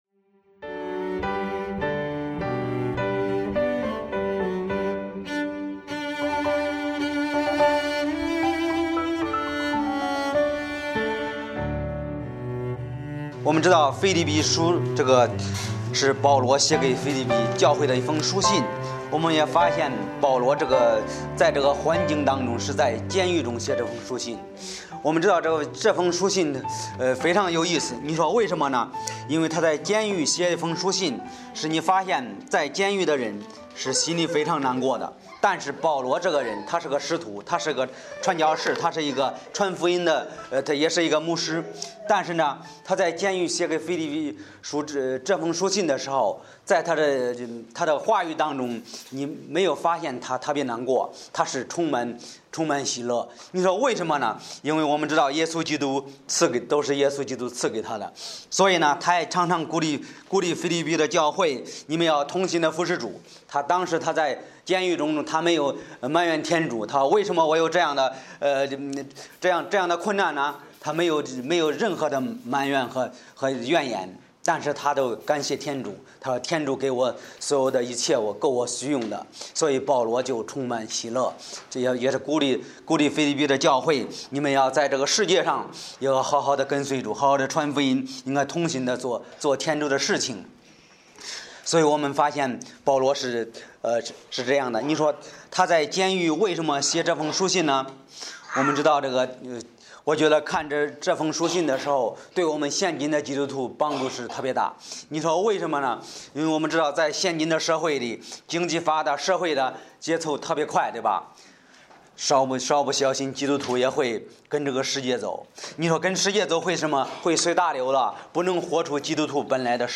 保罗的祝福 – 真柱浸信教会